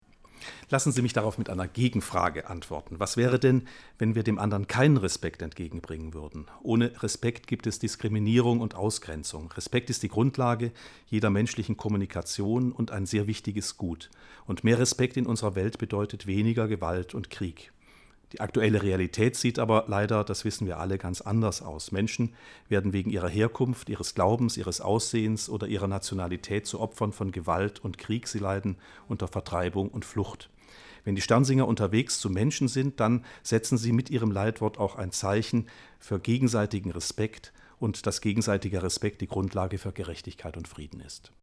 Die Sternsinger' im Interview